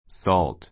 salt 中 A2 sɔ́ːlt ソ ー るト 名詞 塩 , 食塩 put salt on an egg put salt on an egg 卵に塩をかける ⦣ × a salt, × salt s としない.